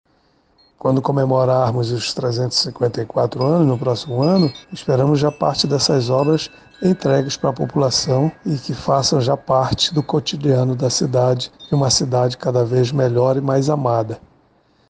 Sonora-Carlos-Valente-diretor-presidente-do-Implurb.mp3